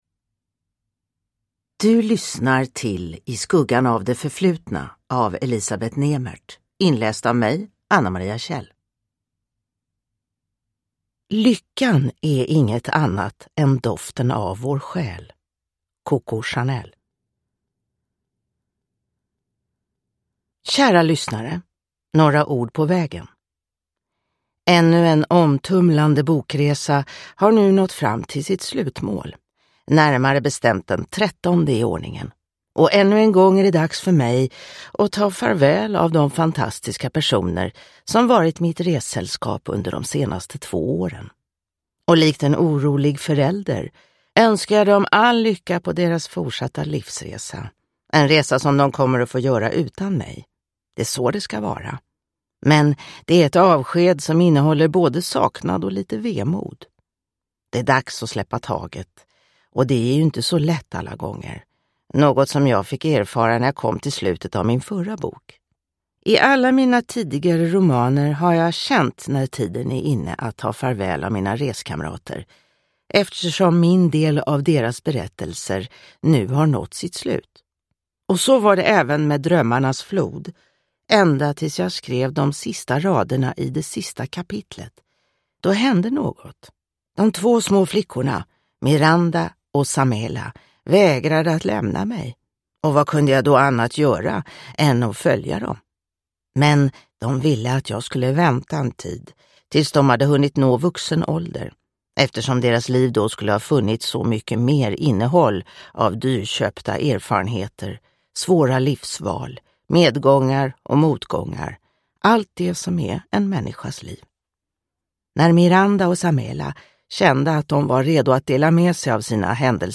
I skuggan av det förflutna (ljudbok) av Elisabet Nemert